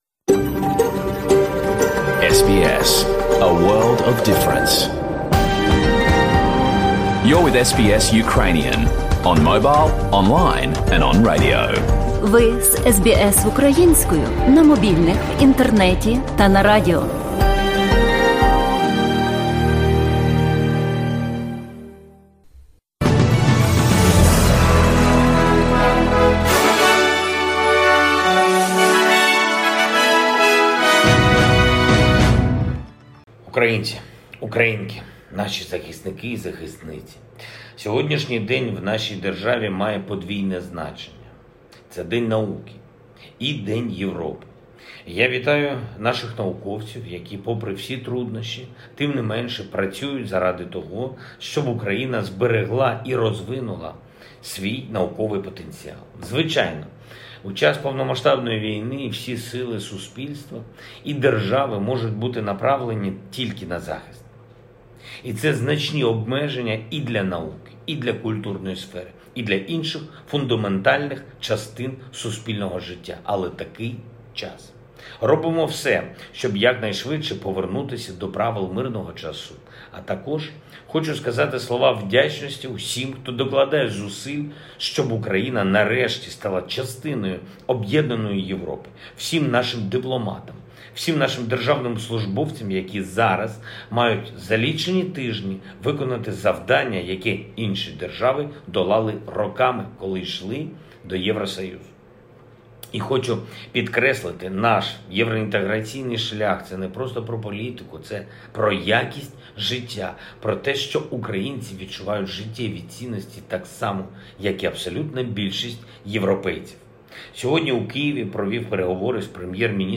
Звернення Президента України Володимира Зеленського